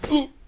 Hit2.wav